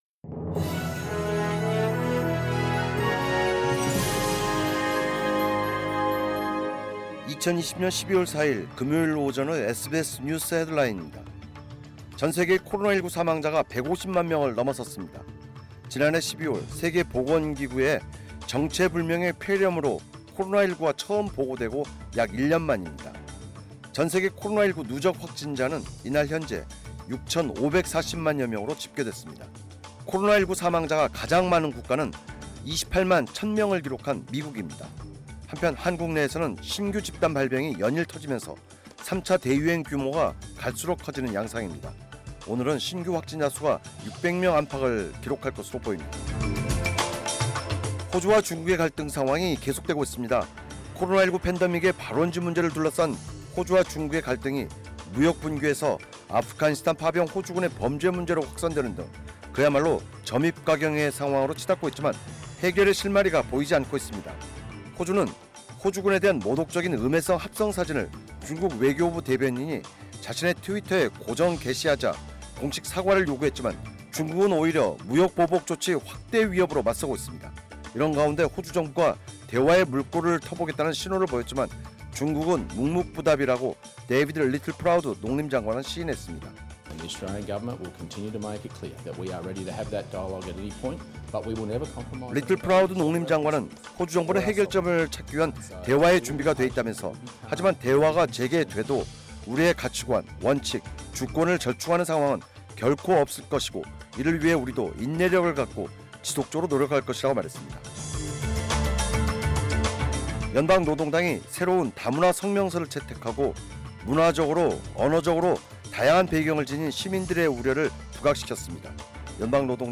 "SBS News Headlines" 2020년 12월 4일 오전 주요 뉴스